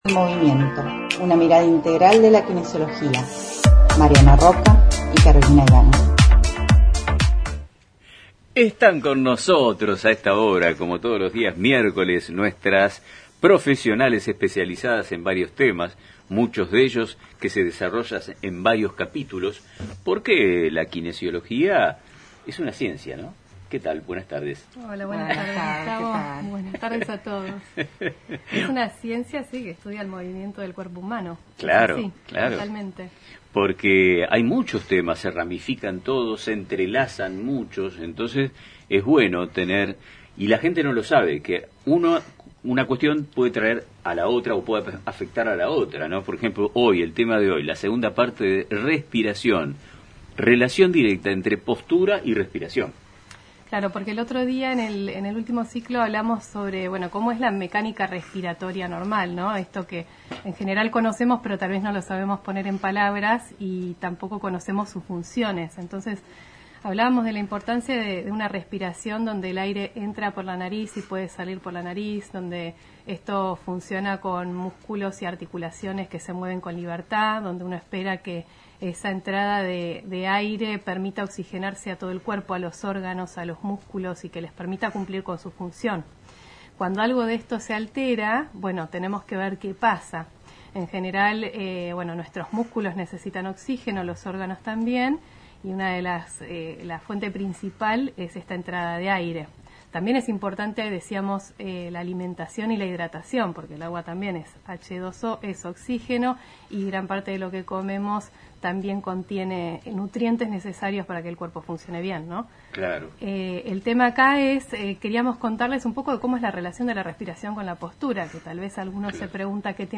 ofrecieron una valiosa charla sobre la importancia de la respiración y su estrecha relación con la postura, continuando con el ciclo de temas que abordan semanalmente.